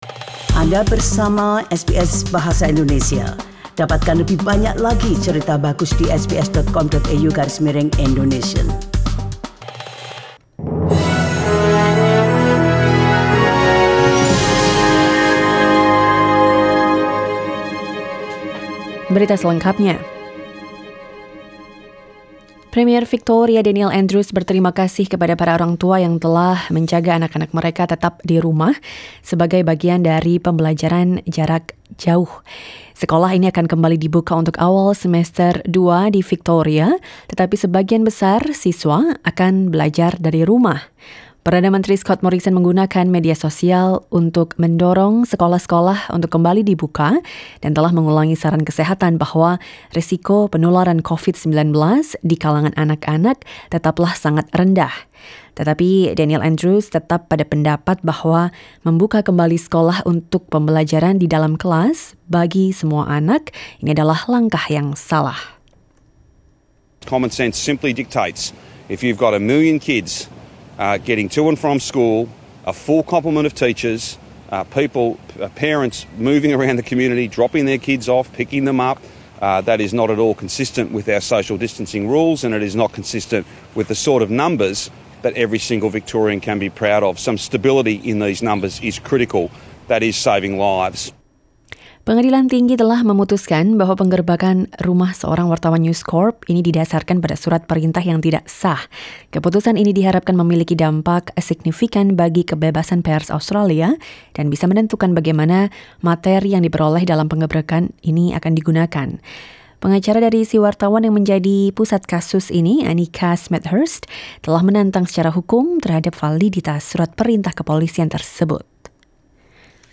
SBS Radio news in Indonesian - 15 April 2020